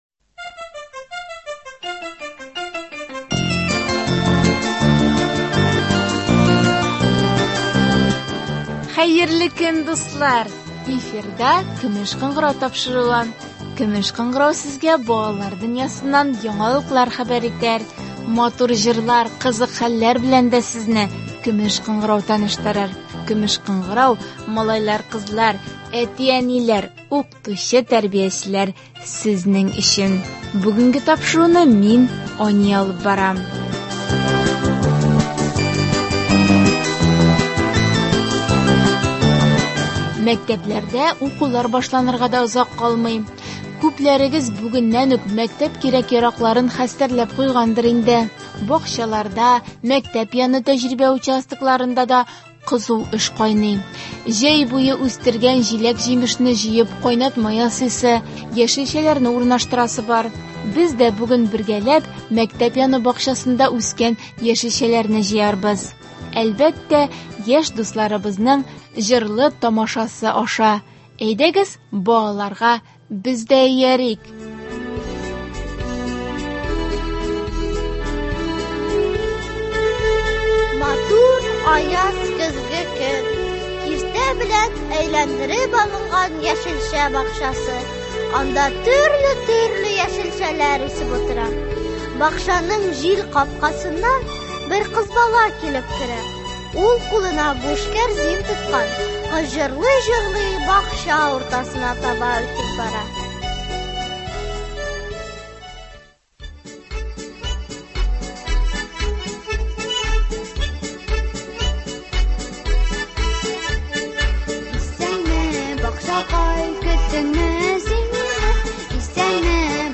Без дә бүген бергәләп мәктәп яны бакчасында үскән яшелчәләрне җыярбыз. Әлбәттә , яшь дусларыбызның җырлы-тамашасы аша.